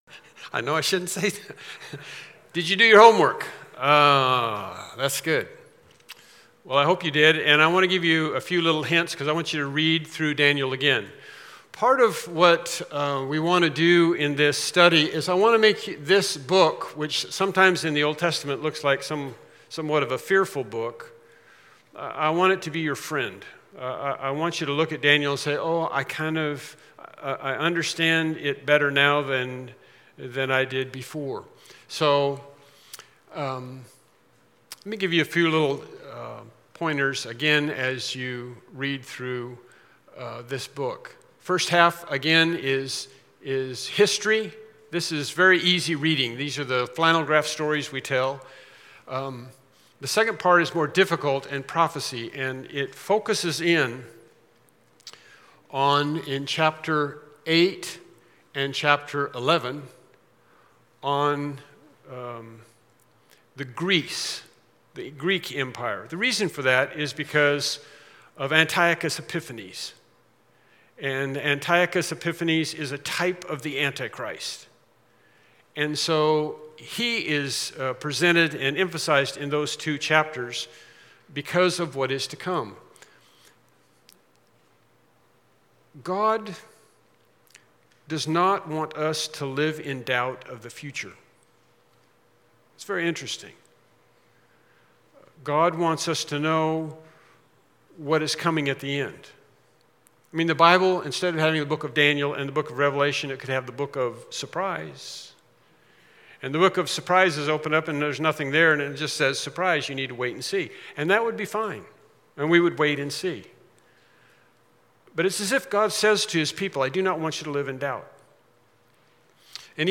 Daniel 9 Service Type: Evening Worship Service « “The Way of Cain” “Daniel